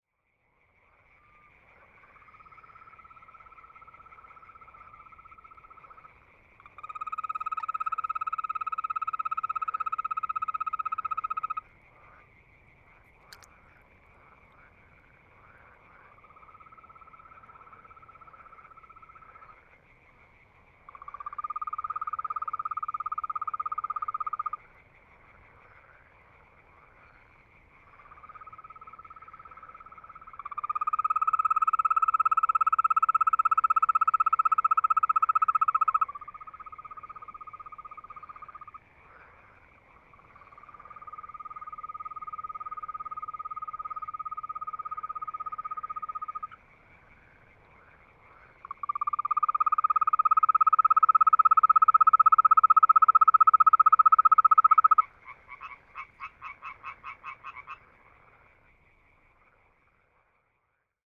Los incluidos en este primer álbum de la colección —ambientes nocturnos y crepusculares captados en diversas regiones de France métropolitaine— fueron realizados a lo largo de una década, entre 2012 y 2022, durante las distintas estaciones del año.
Début de nuit en Corse-du-sud, crapauds verts (3:23); 5.